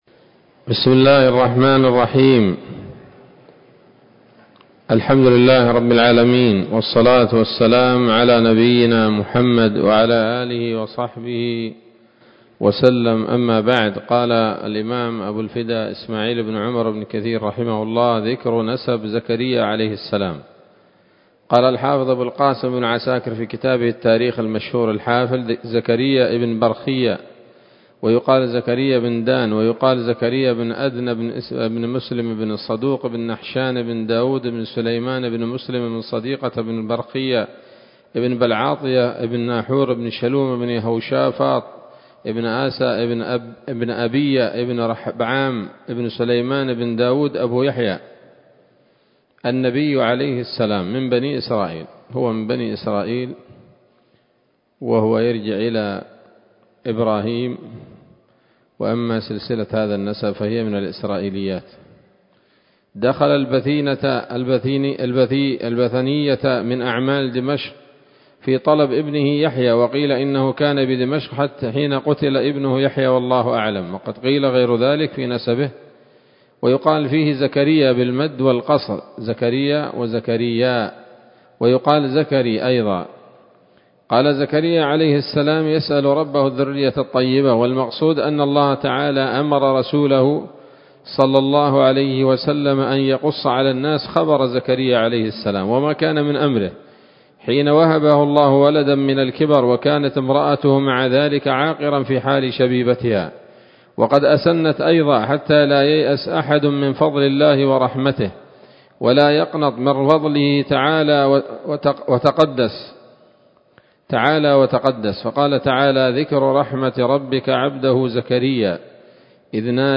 ‌‌الدرس الثالث والثلاثون بعد المائة من قصص الأنبياء لابن كثير رحمه الله تعالى